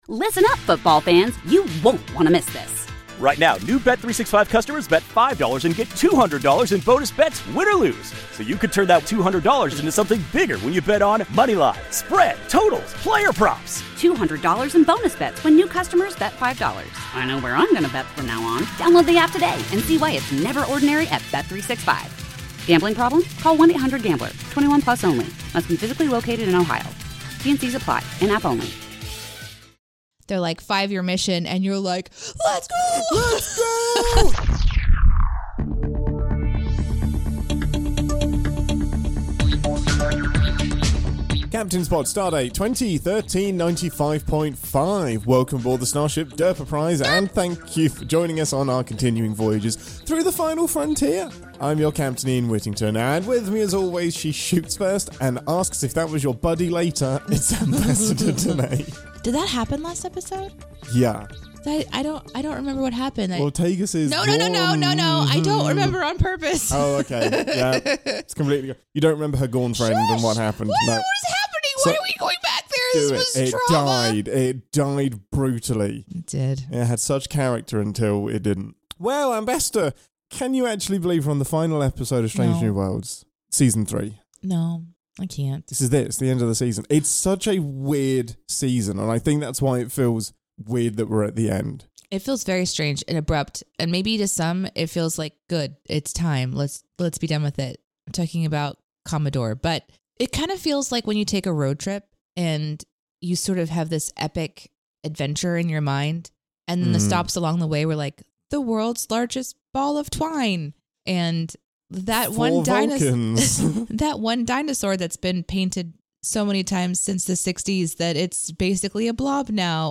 (1:15:38) 3) The Jefferies Tubes - Bloopers and other goodies that didn’t make it into the show.